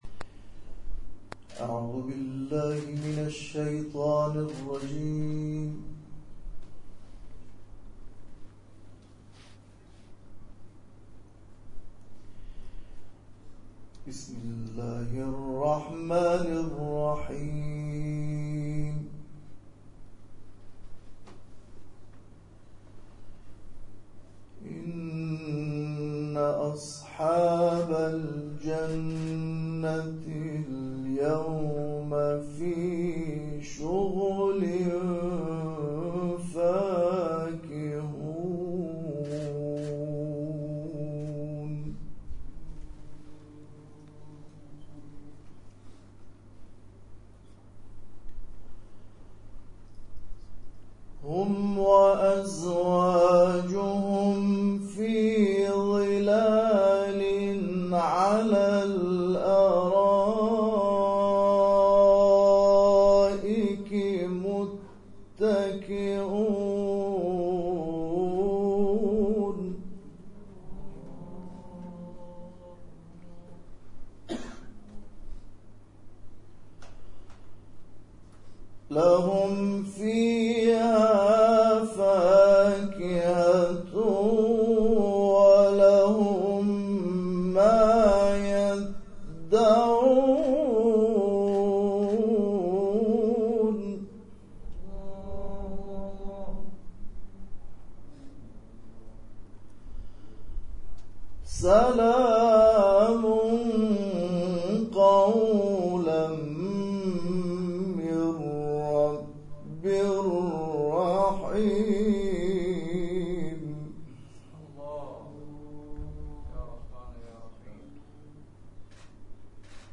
جدیدترین تلاوت
گروه جلسات و محافل: ششمین کرسی تلاوت تسنیم در سالن اجتماعات باشگاه خبرنگاران پویا